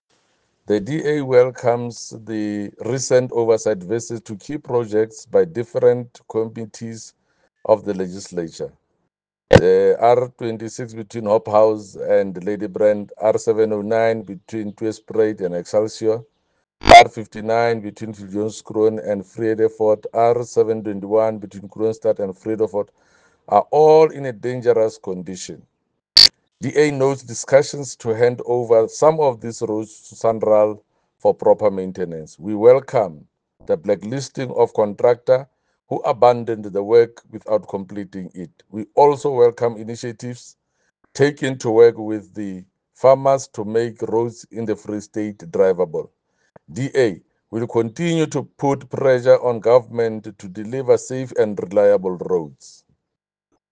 Sesotho soundbites by Jafta Mokoena MPL with pictures here, and here